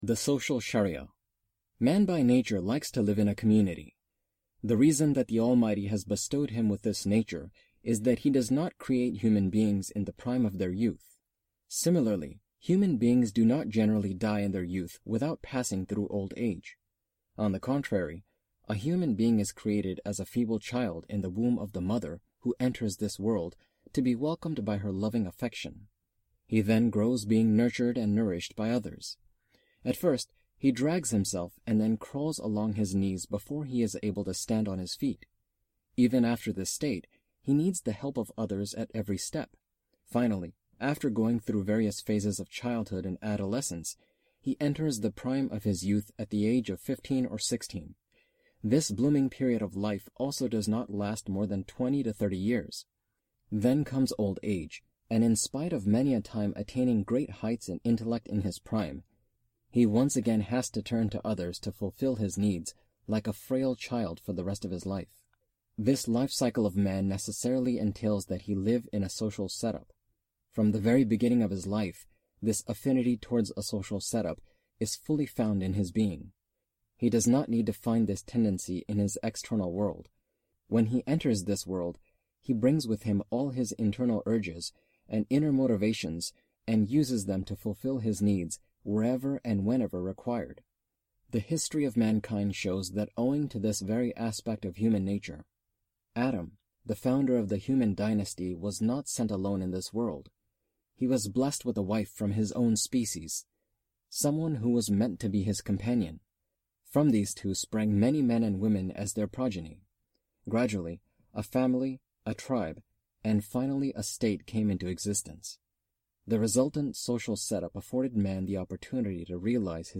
Audio book of English translation of Javed Ahmad Ghamidi's book "Mizan".